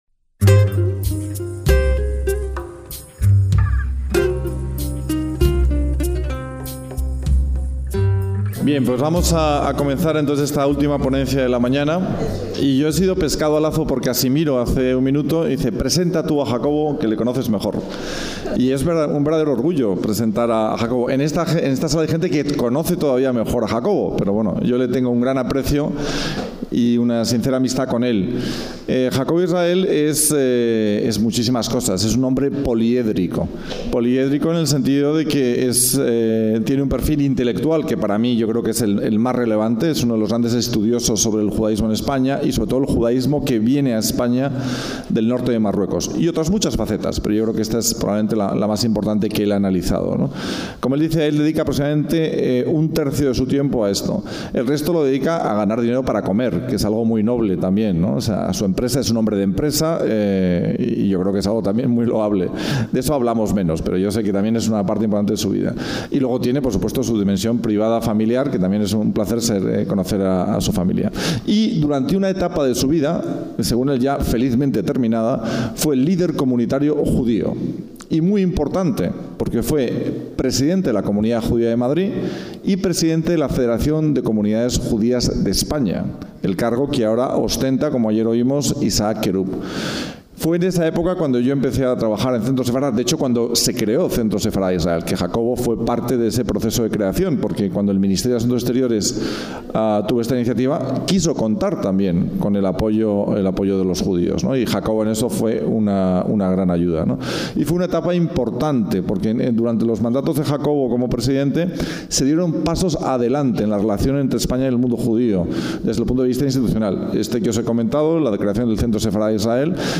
DESDE LAS X JORNADAS SEFARDÍES EN LA RIOJA